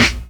kits/RZA/Snares/GVD_snr (29).wav at main
GVD_snr (29).wav